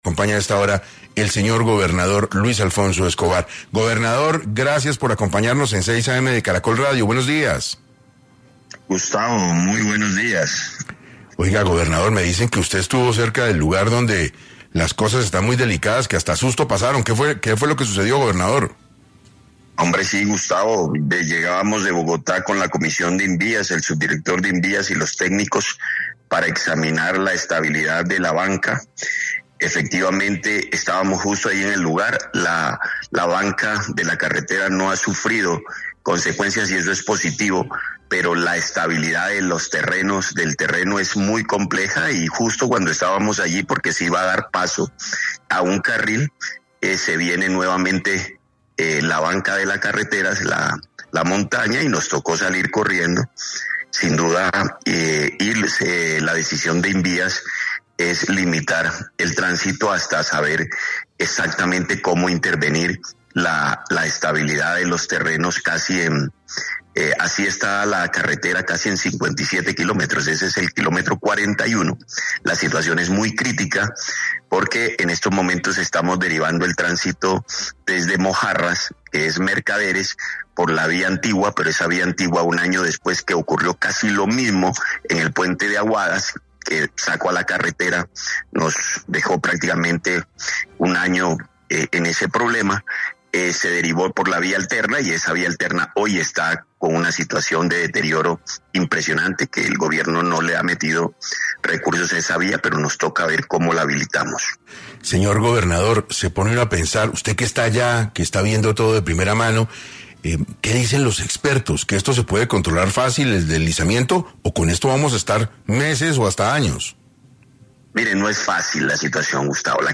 En 6AM habló Luis Alfonso Escobar, gobernador de Nariño sobre la situación que se vive en la zona por el cierre de la vía Panamericana.
En medio de la entrevista, el gobernador comentó que se dirigía a La Unión y a Mojarras para hablar con la población y llegar a un acuerdo que permita el transito de algunos camiones de menos de 50 toneladas, pero que disminuya el desabastecimiento en Nariño.